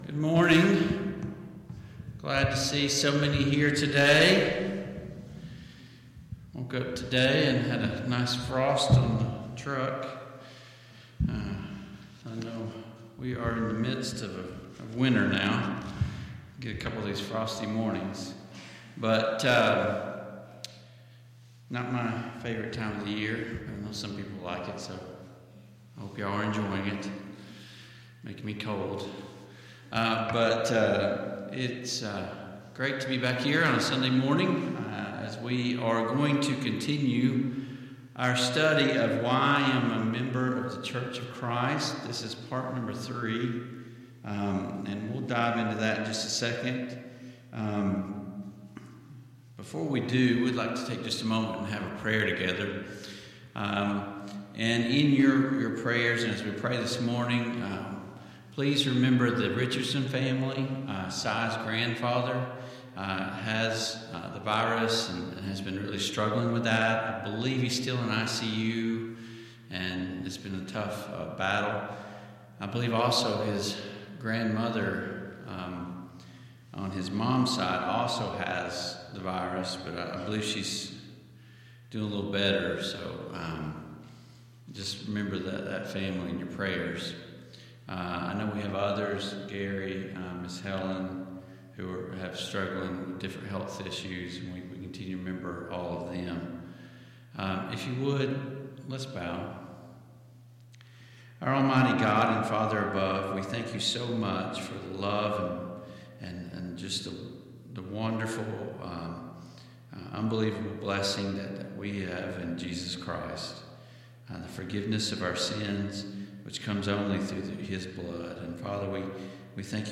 Service Type: Sunday Morning Bible Class Topics: Membership in the Church , Worship